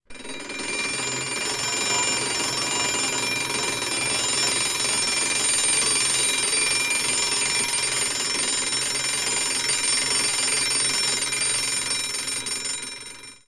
classic-alarm-clock-bell